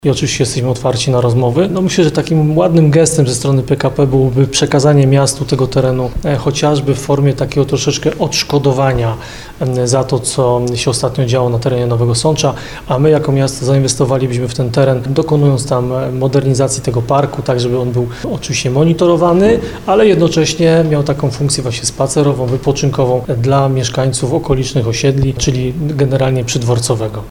Miasto Nowy Sącz jest zainteresowane przejęciem tego terenu, a prezydent Ludomir Handzel informuje, że do PKP trafiło już pismo w tej sprawie.